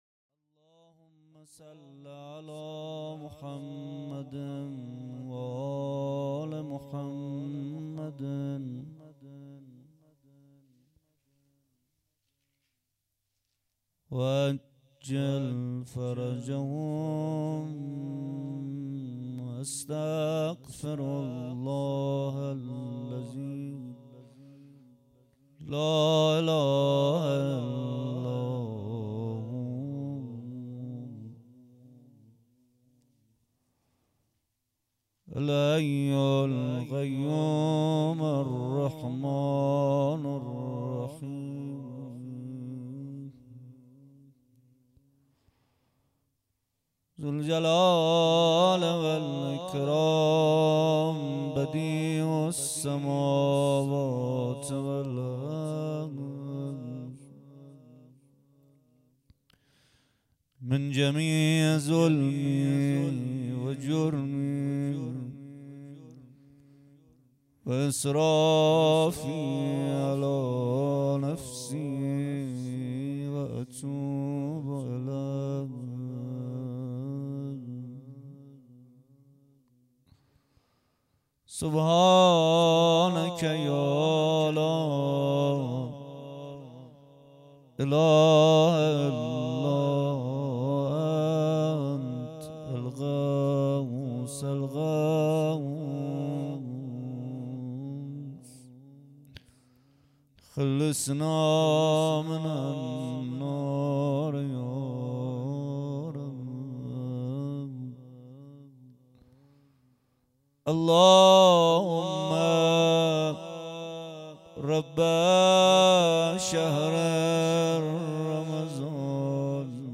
محمدحسین پویانفر مداح اهل بیت(ع) در اولین شب ماه رمضان در جمع جهادگران هیأت ریحانةالنبی(س) به مناجات‌خوانی پرداخت.
هیأت ریحانةالنبی (س) این شب‌ها در قالب خیریه همدردی خود برای کمک‌رسانی به نیازمندان مشغول فعالیت است و برگزاری جلسه مجازی خود را نیز به مسجد گیاهی تجریش محل فعالیت این گروه منتقل کرده است.